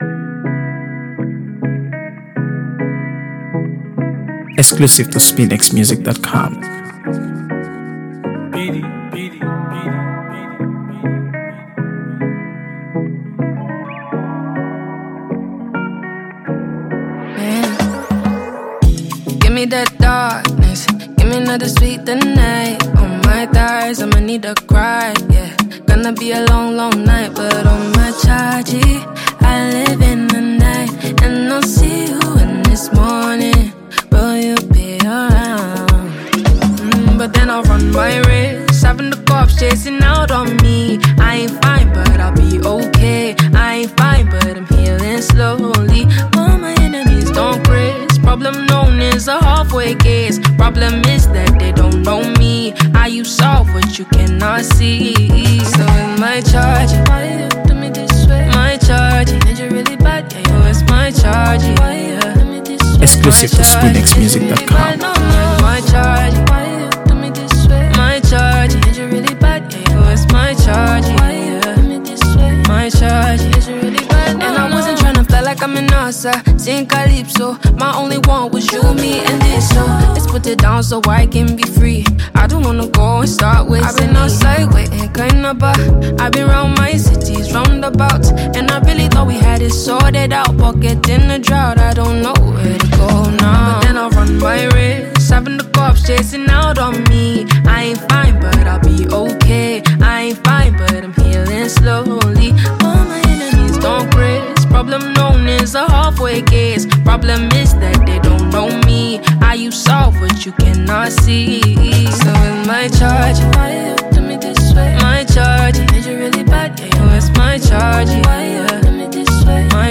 AfroBeats | AfroBeats songs
Nigerian singer-songwriter